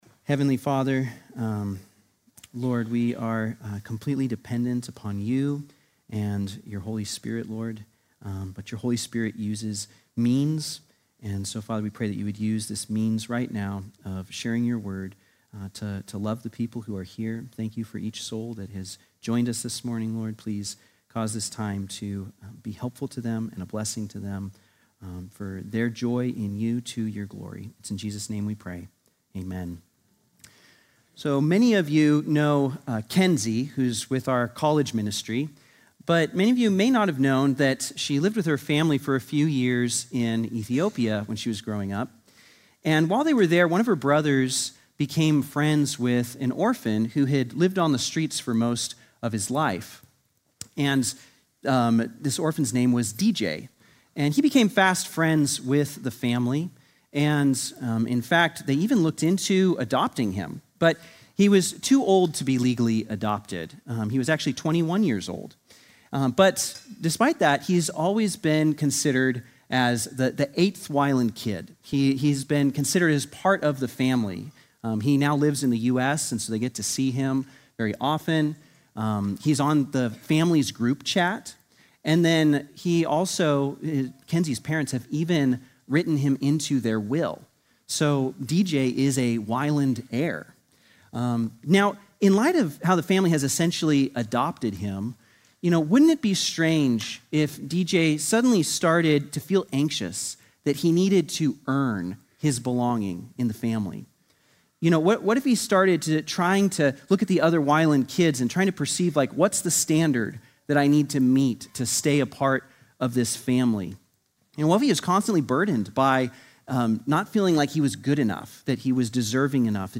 Sunday morning message December 14
sermon